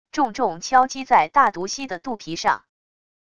重重敲击在大毒蜥的肚皮上wav音频